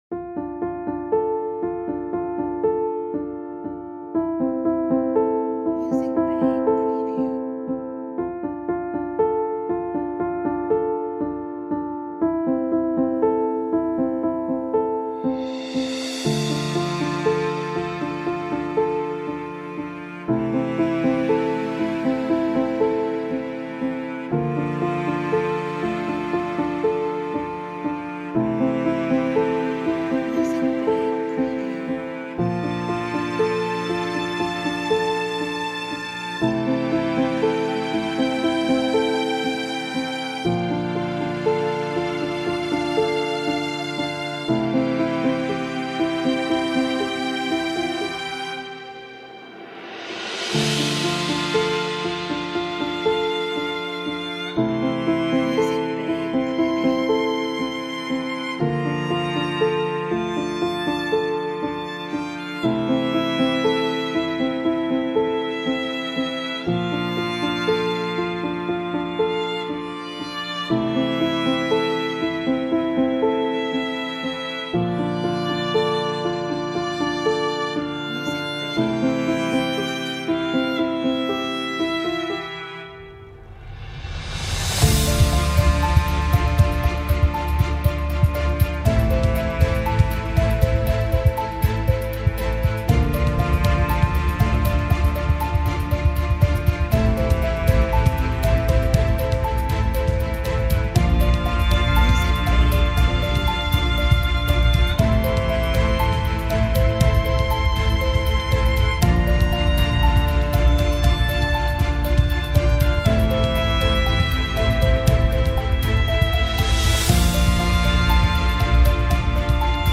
inspirational background music